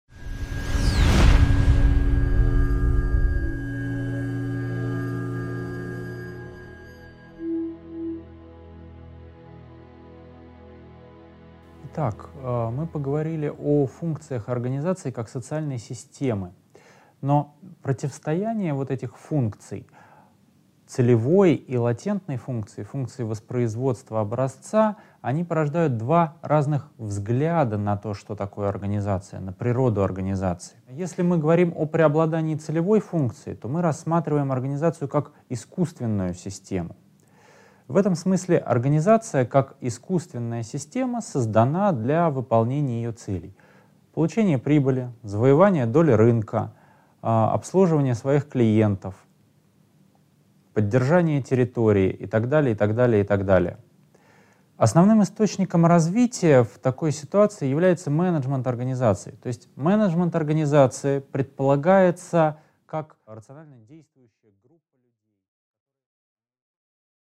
Аудиокнига 1.5. Естественная, искусственная системы | Библиотека аудиокниг